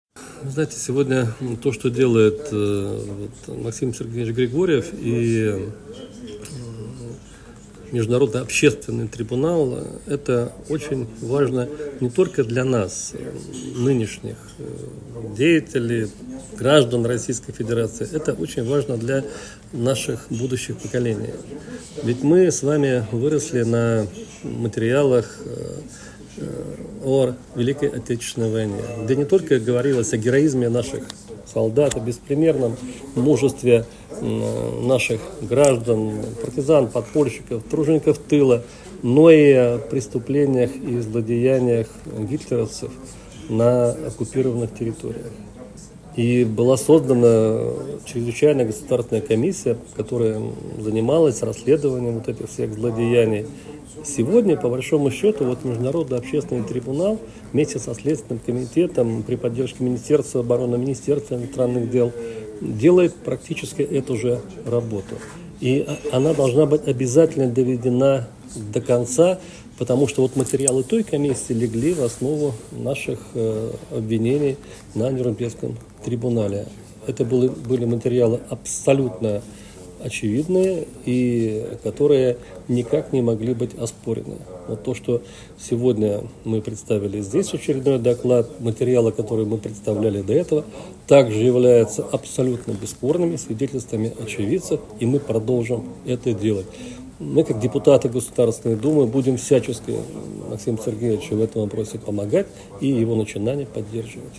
ГЛАВНАЯ > Актуальное интервью
Председатель Комитета Государственной Думы по обороне Андрей Картаполов в интервью журналу «Международная жизнь» рассказал о деятельности и значении Международного общественного трибунала и его председателя Максима Григорьева: